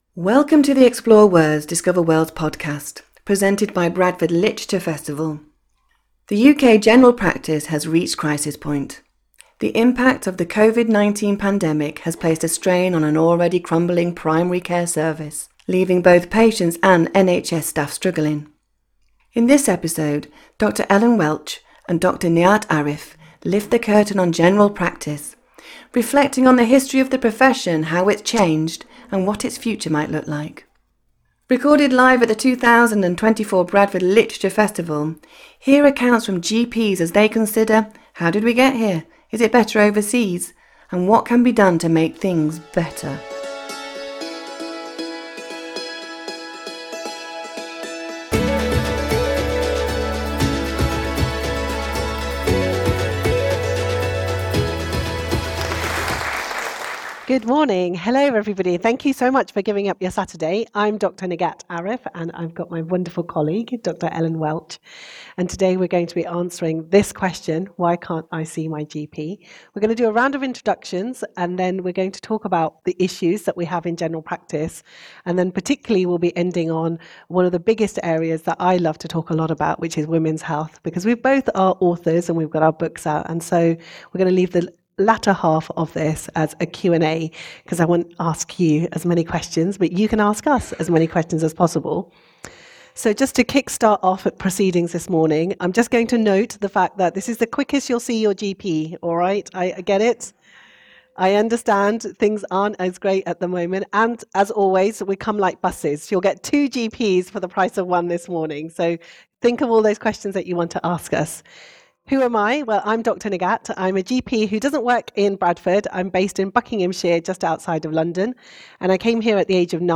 Join us for a captivating discussion